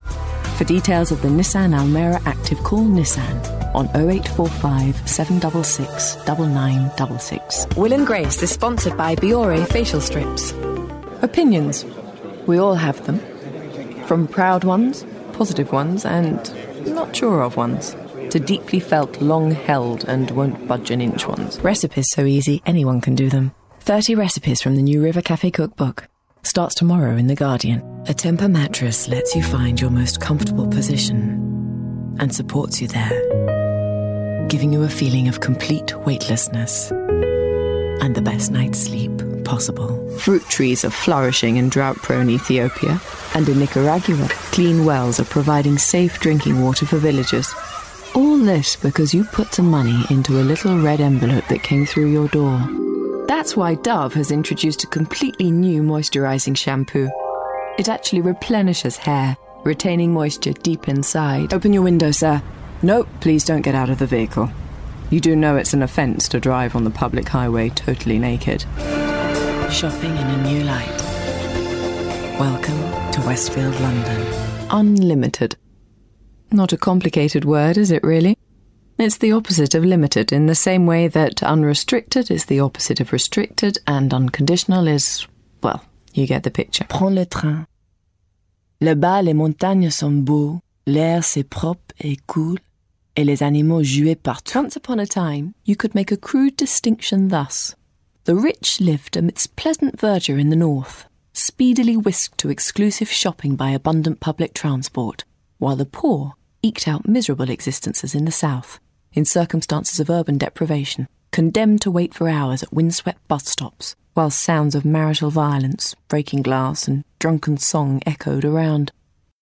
Commercial Showreel